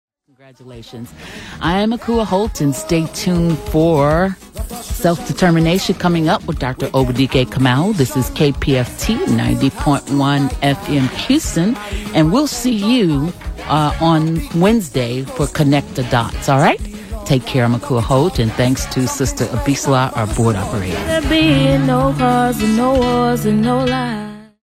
KPFT Top of the Hour Audio: